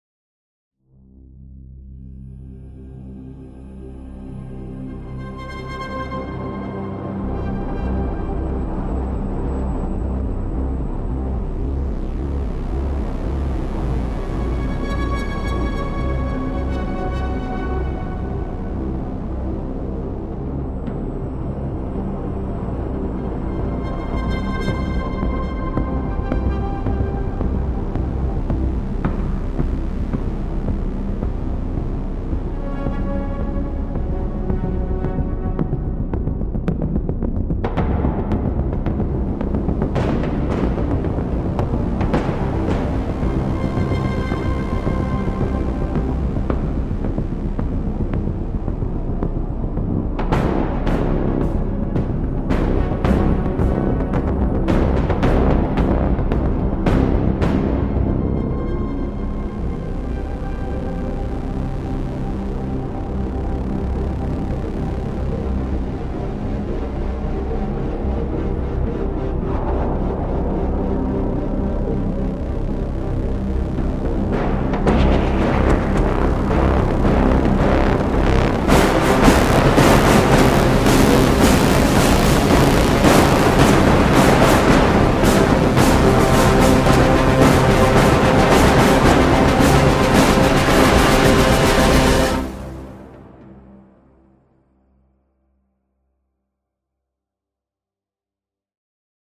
Hybrid horror track for trailers and cinematic.
Hybrid mystery, horror track for trailers and cinematic.